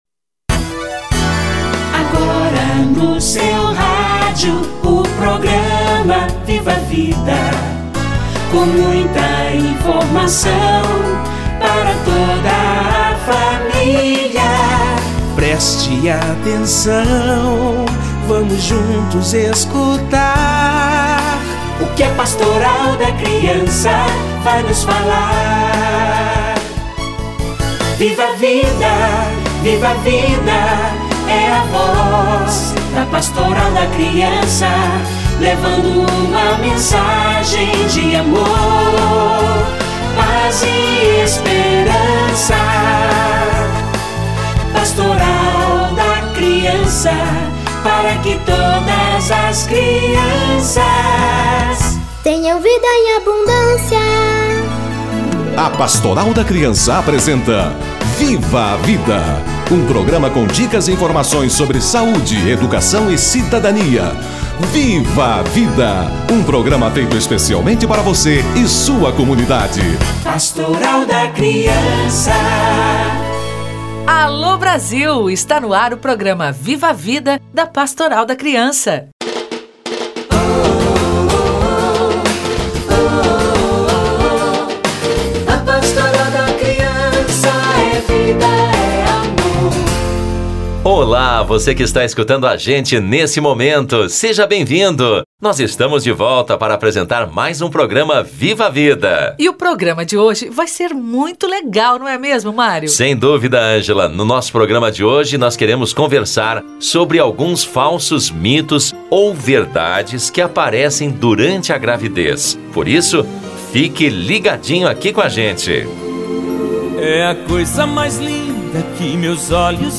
Mitos e queixas da gestante - Entrevista